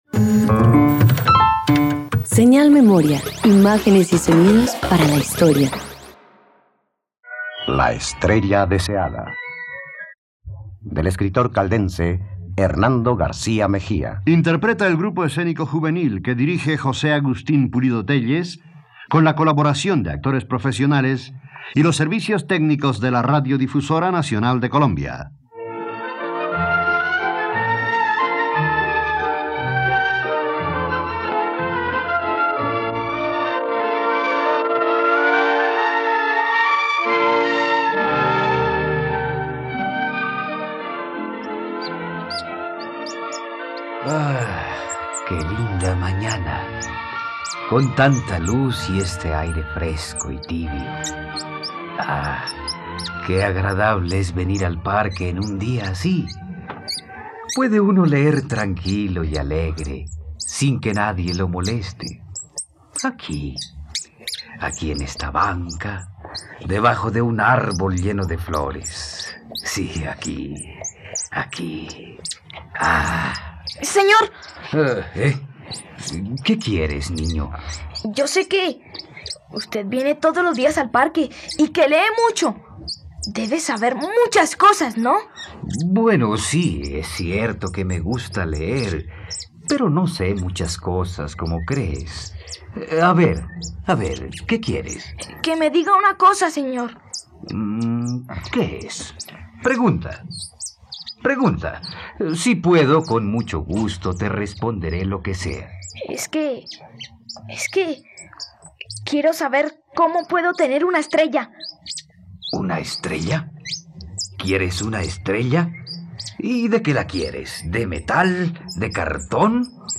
La estrella deseada - Radioteatro dominical | RTVCPlay